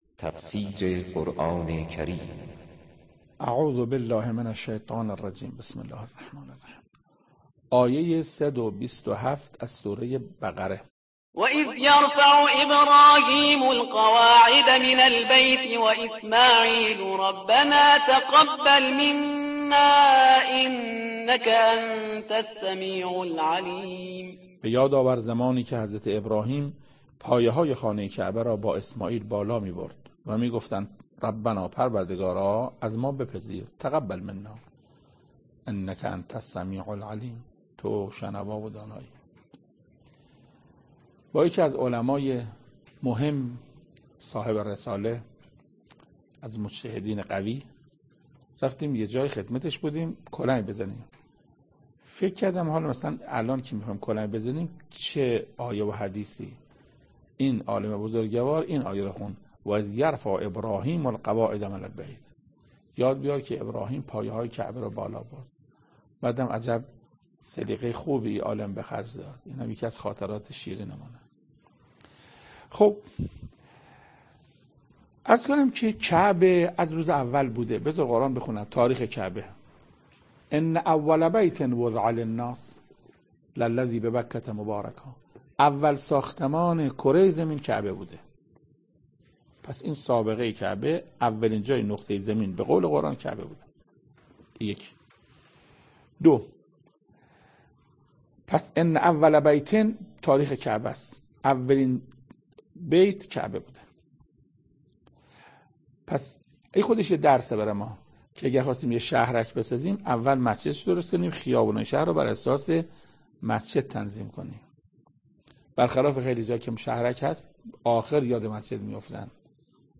تفسیر صد و بیست و هفتمین آیه از سوره مبارکه بقره توسط حجت الاسلام استاد محسن قرائتی به مدت 7 دقیقه